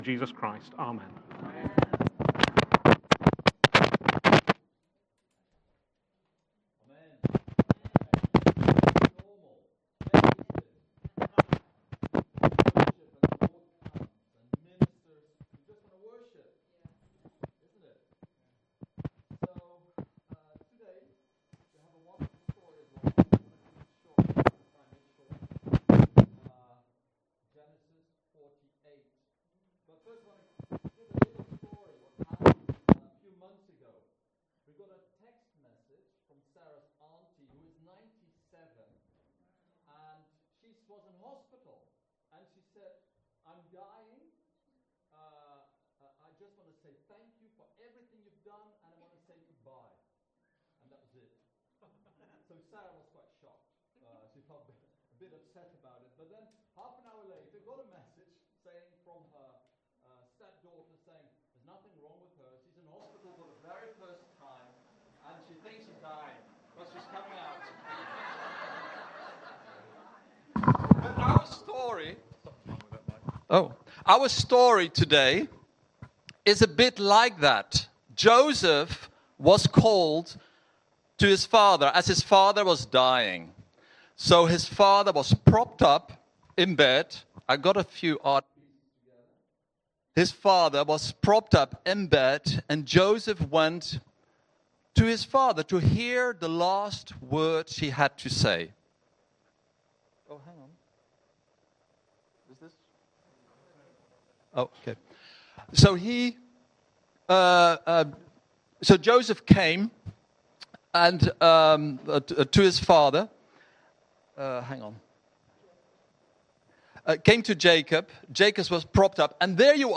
Sunday Gathering – Genesis – The ages of man
Welcome to this week’s message from our church, right here in the heart of our community.